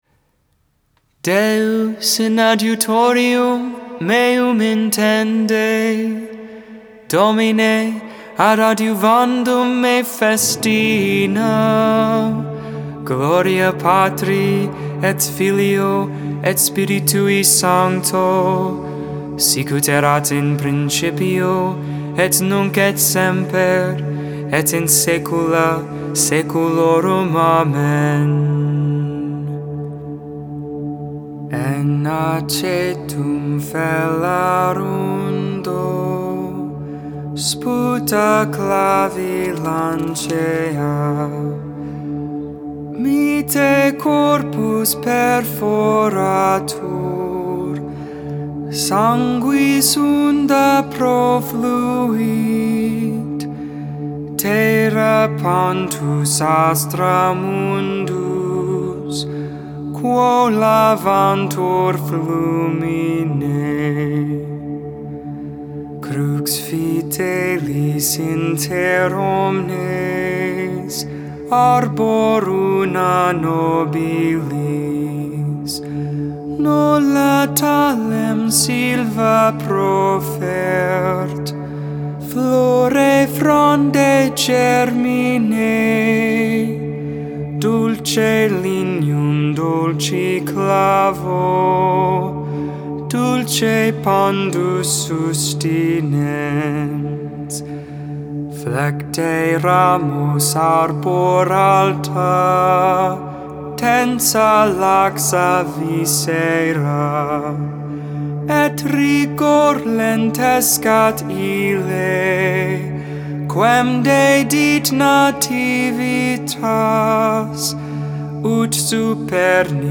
Benedictus (English, Tone 8, Luke 1v68-79) Intercessions: "Lord Have Mercy on us."